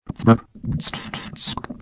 It's not so hard to say once you figure out how to speak without vowels.
1. Pth is like pith without the i. Purse your lips together to make a "p", when the pop open your toungue should already be between your teeth to make the "th"- like hissing with a lisp.
2. brp is like burp without the u. Or is that like brap without the a?
Speak it up without any vowels.